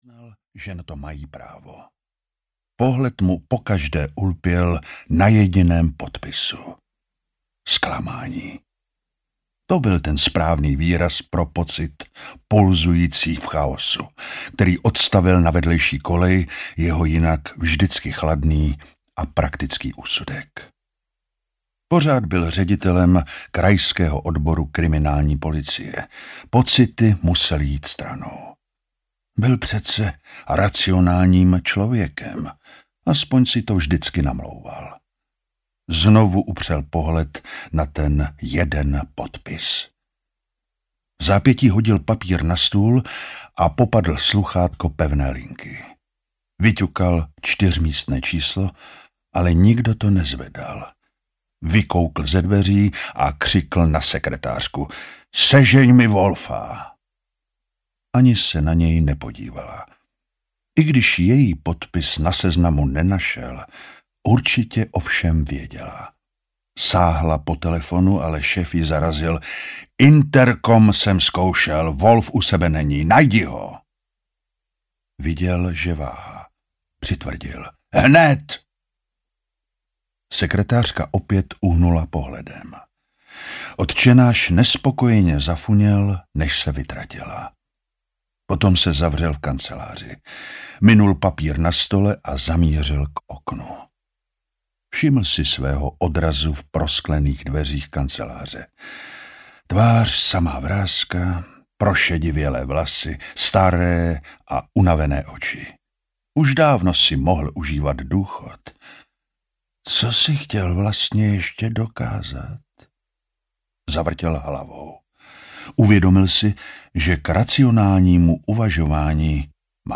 Kukačka audiokniha
Ukázka z knihy
kukacka-audiokniha